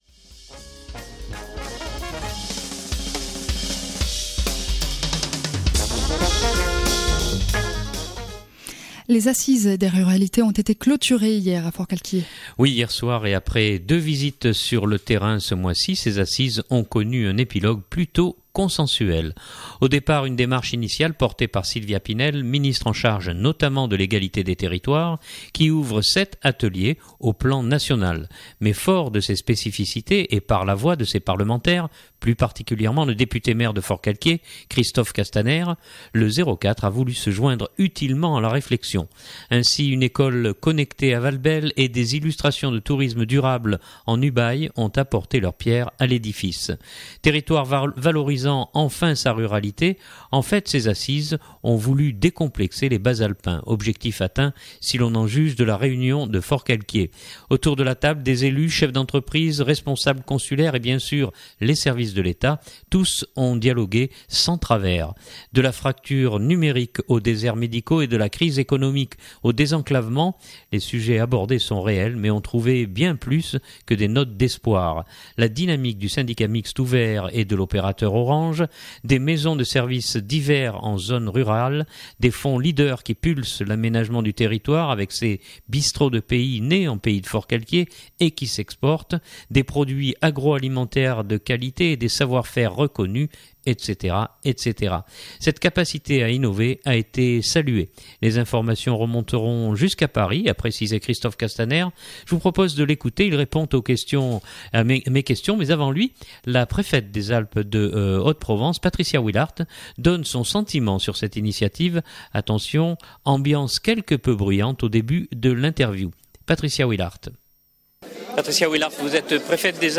Attention ambiance quelque peu bruyante au début de l’interview.